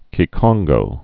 (kē-kŏnggō)